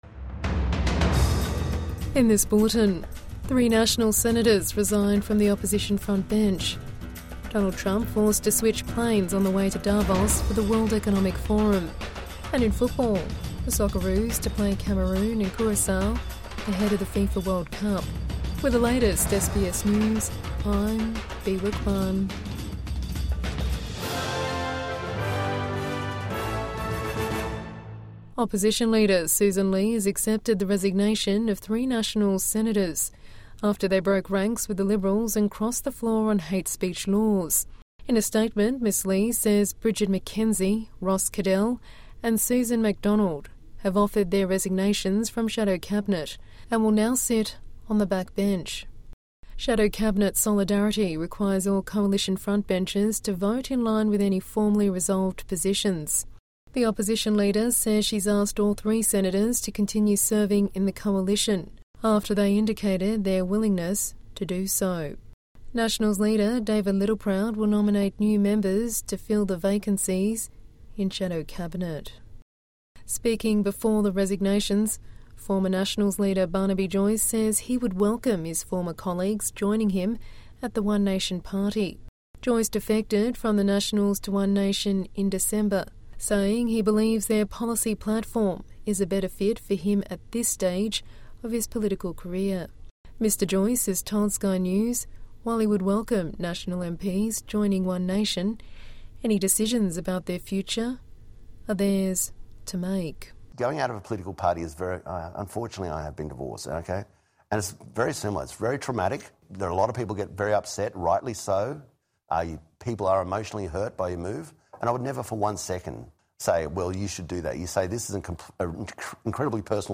Three Nationals senators resign from opposition frontbench | Evening News Bulletin 21 January 2026